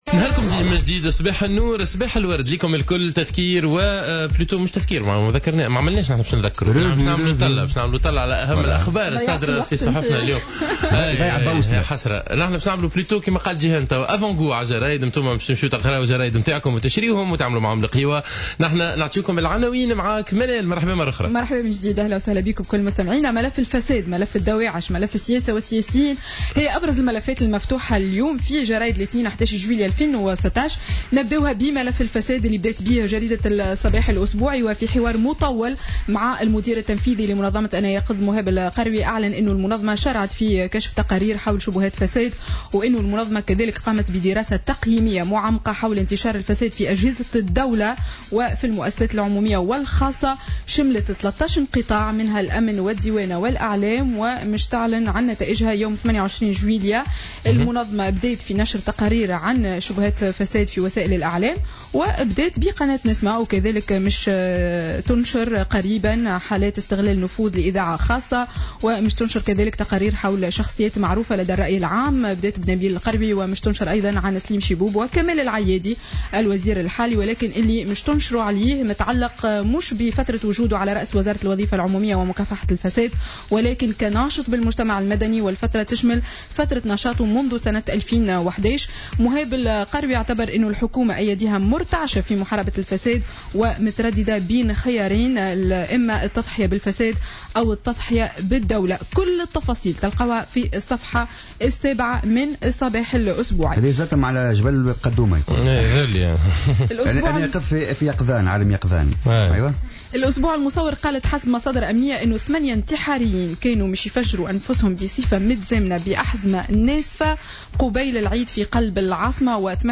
Revue de presse du lundi 11 juillet 2016